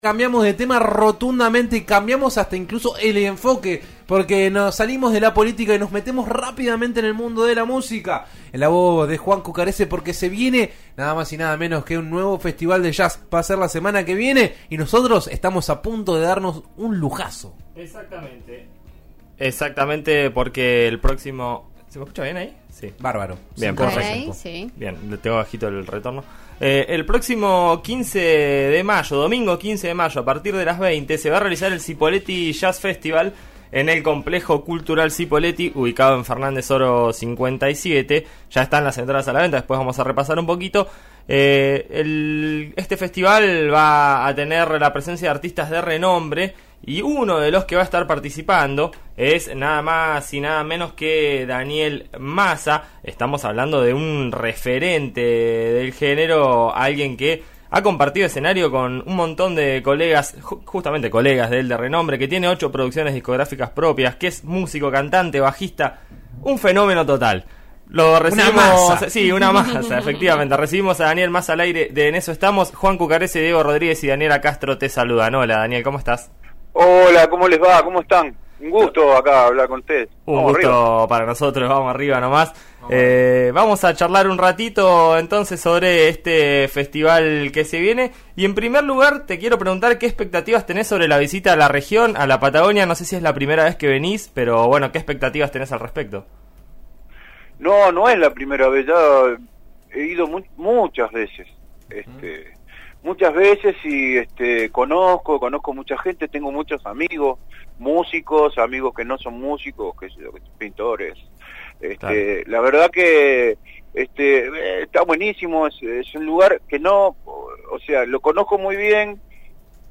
En la previa a la primera edición del Cipolletti Jazz Festival, que se realizará el domingo 15 de mayo, En eso estamos de RN Radio dialogó con el reconocido bajista Daniel Maza, que dirá presente en la región.